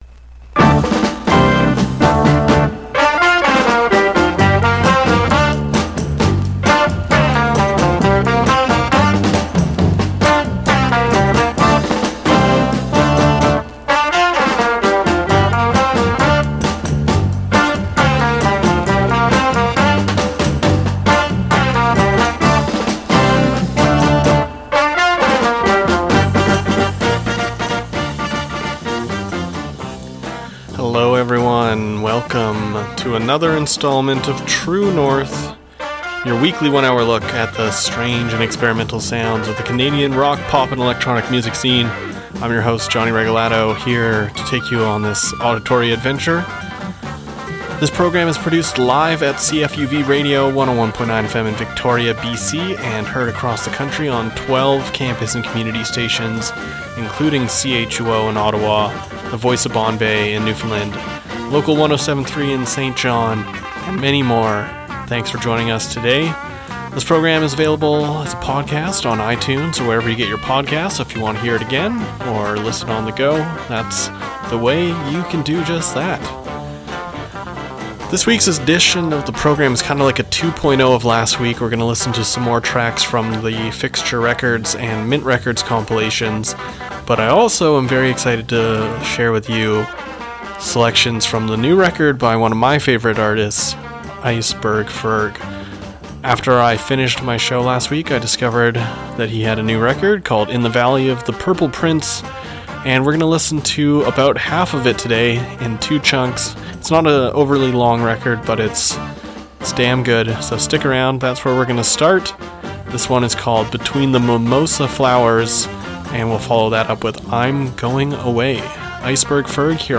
An hour of strange, experimental and independent Canadian rock and pop